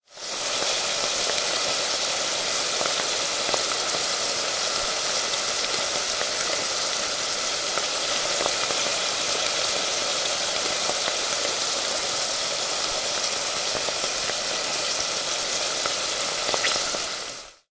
烧烤 烹饪 油炸 烘烤 烧烤
描述：在平底锅里烤。
标签： 烤肉 烧烤 烘烤 嘶嘶声 烹调
声道立体声